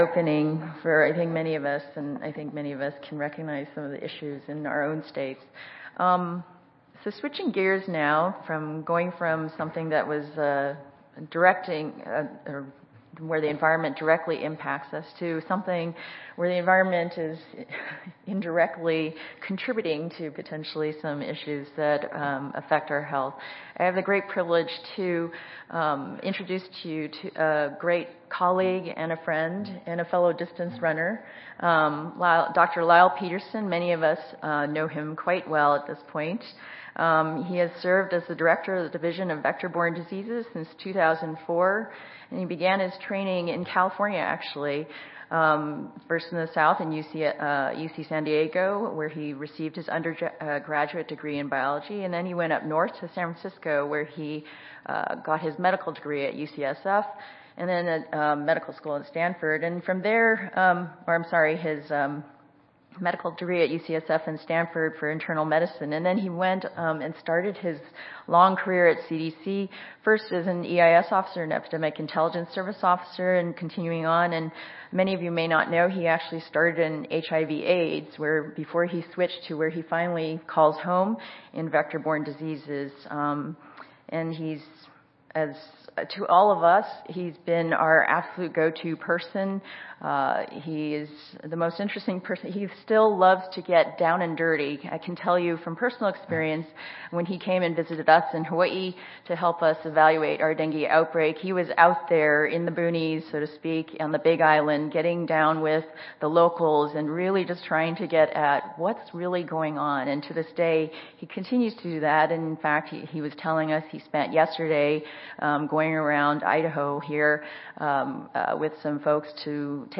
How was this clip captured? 2017 CSTE Annual Conference